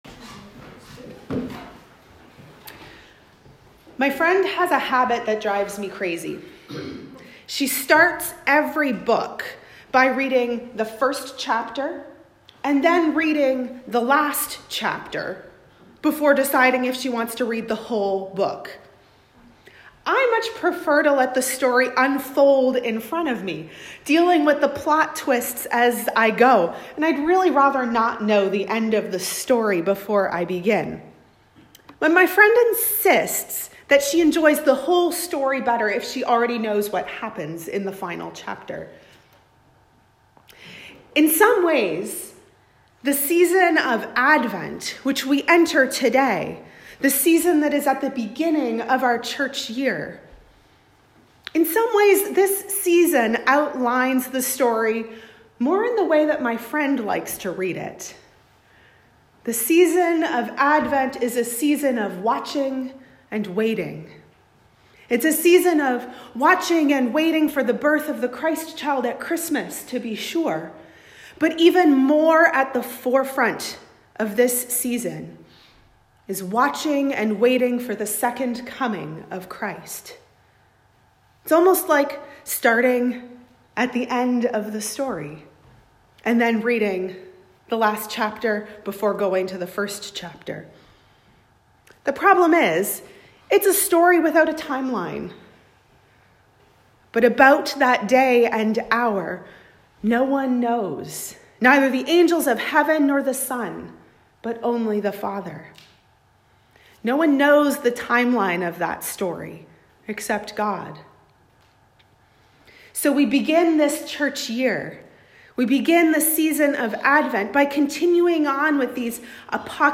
Sermons | Parish of the Valley
Recorded at St George's, South Alice.